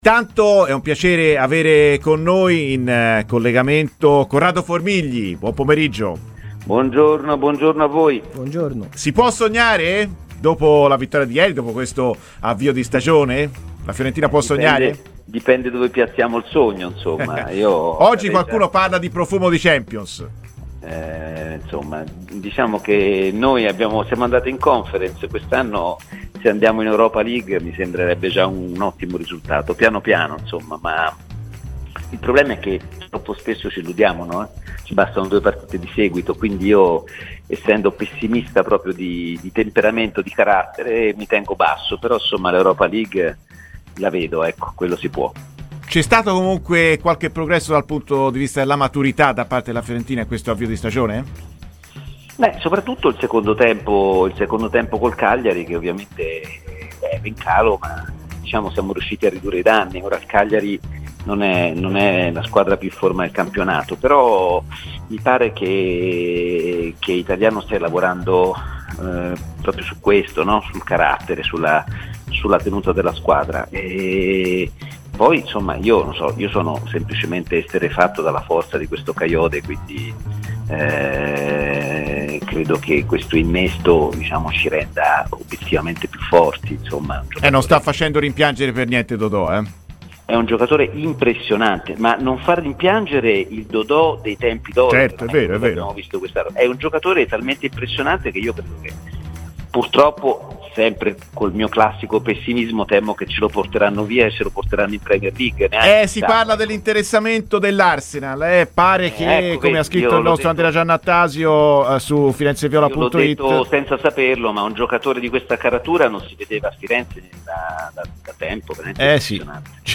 Corrado Formigli, noto giornalista di La7 e grande tifoso della Fiorentina, è intervenuto oggi ai microfoni di Radio FirenzeViola, nel corso della trasmissione "Viola amore mio", iniziando dagli obiettivi stagionali: "Io sono pessimista di natura, per cui metto freno agli entusiasmi. Per me se riuscissimo ad andare in Europa League sarebbe un ottimo risultato".